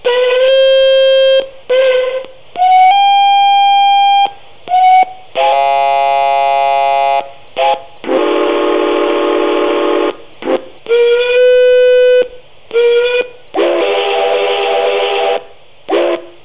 Sample Steam Sounds
A selection of whistles from Australia and New Zealand starting with Puffing Billy.
australian_whistles.wav